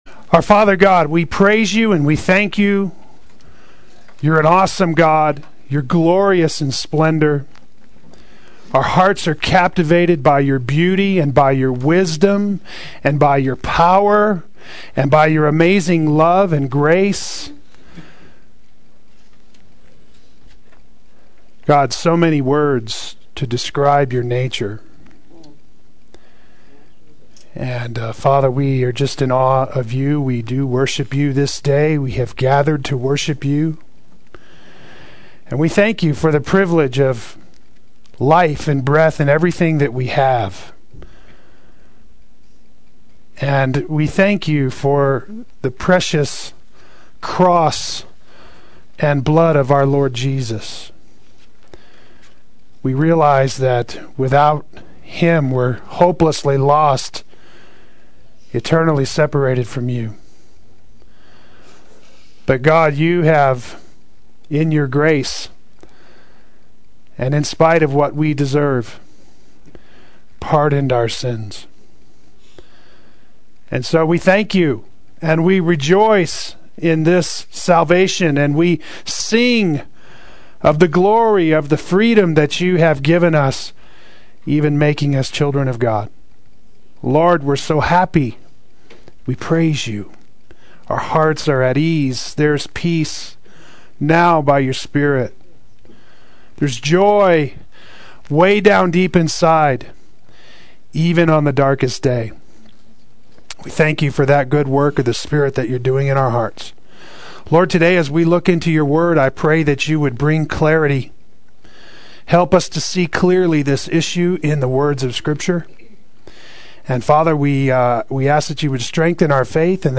Play Sermon Get HCF Teaching Automatically.
Declaring War on Pride Adult Sunday School